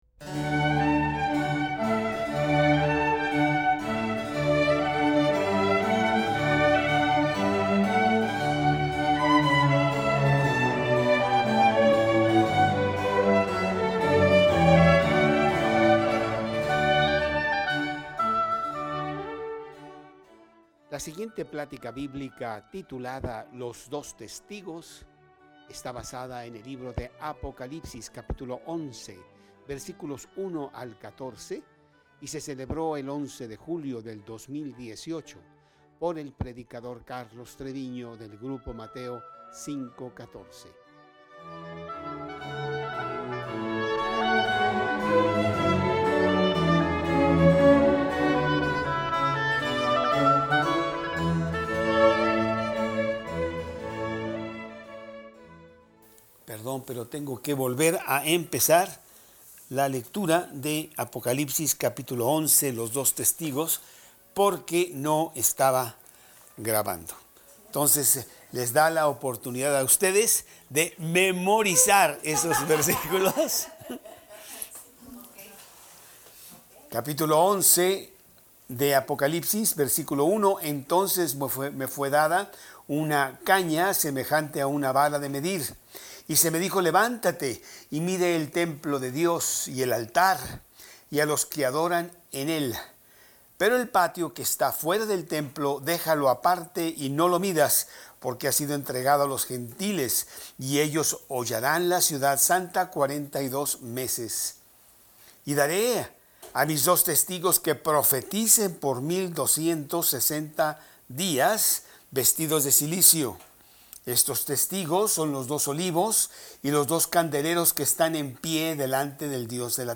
2018 Los Dos Testigos Preacher